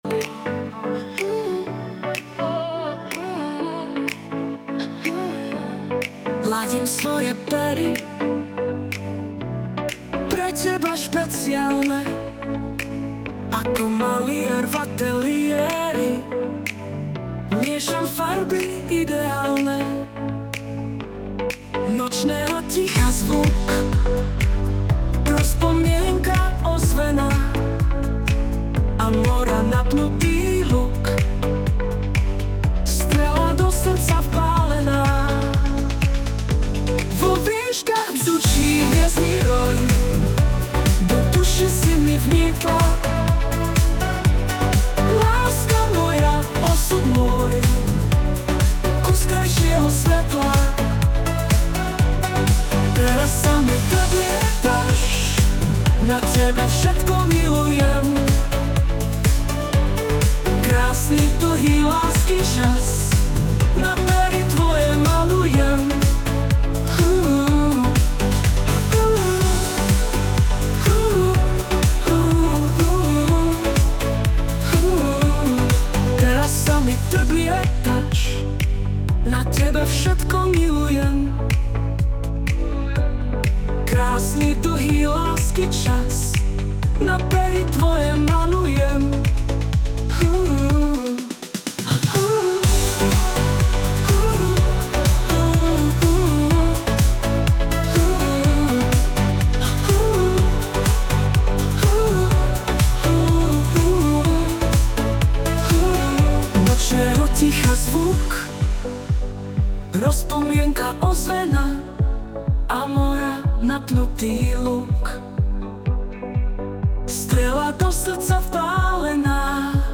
hudba, spev AI